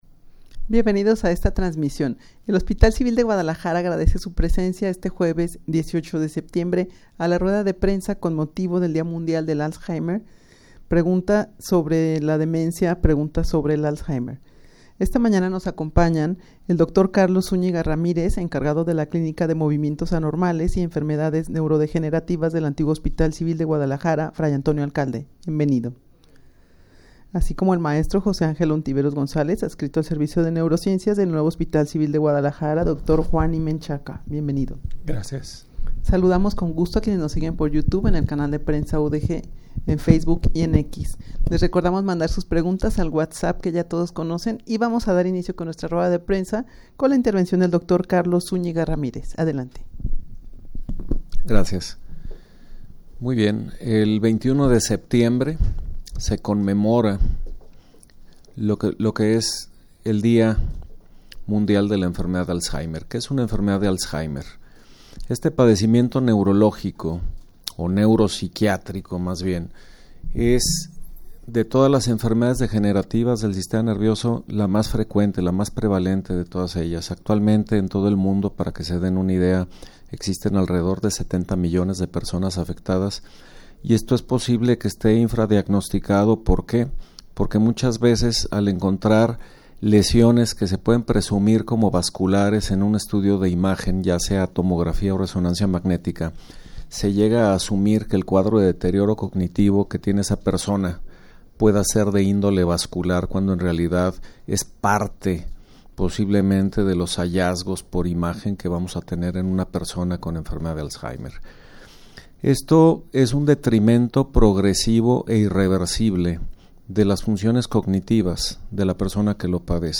Audio de la Rueda de Prensa
rueda-de-prensa-con-motivo-del-dia-mundial-del-alzheimer-pregunta-sobre-la-demencia-pregunta-sobre-el-alzheimer.mp3